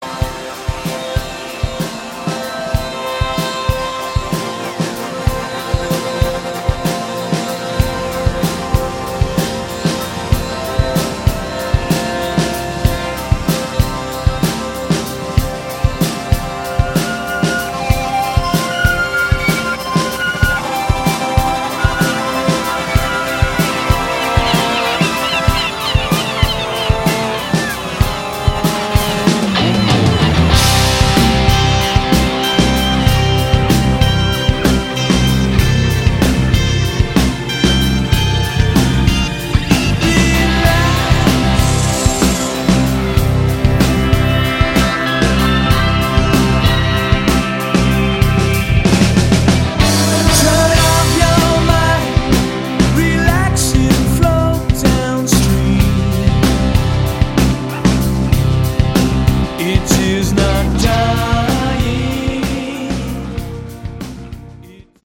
Category: Hard Rock
vocals
guitars
bass
keyboards
drums and percussion